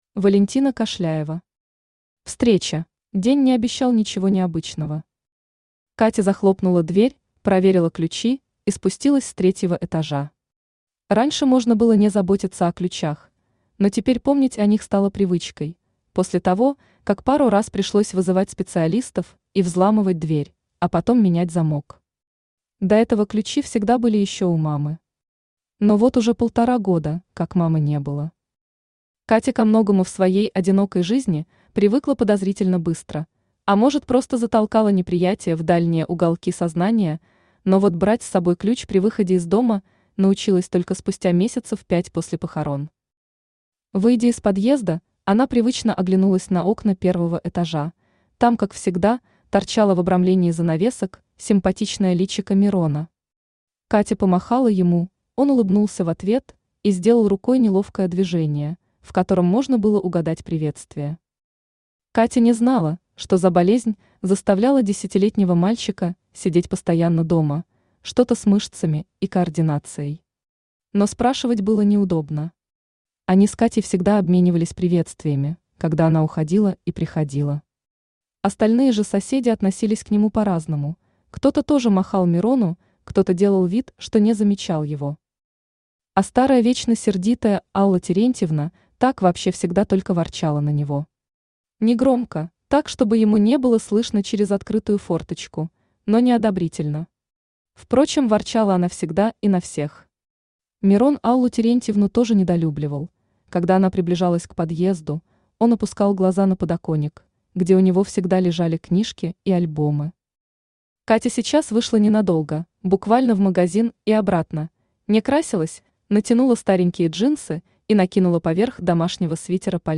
Aудиокнига Встреча Автор Валентина Викторовна Кашляева Читает аудиокнигу Авточтец ЛитРес.